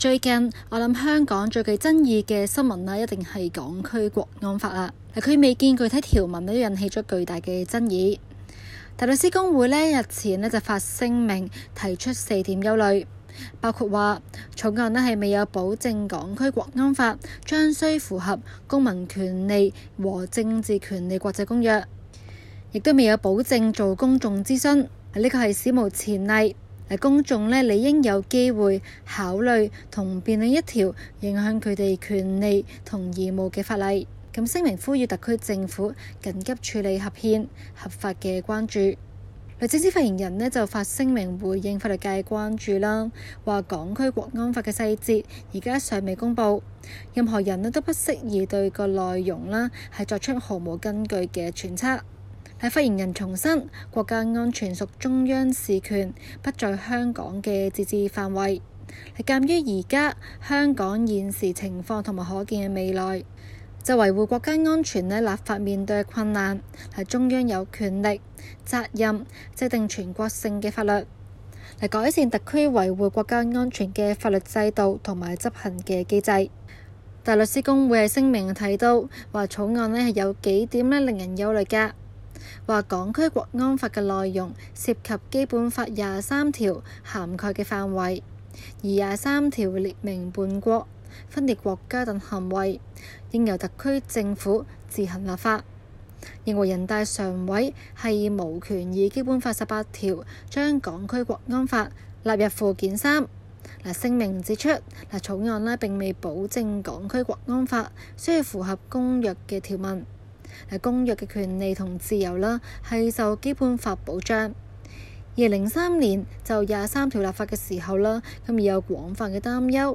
今期 【中港快訊 】環節報道香立法會今天辯論『國歌法』內容。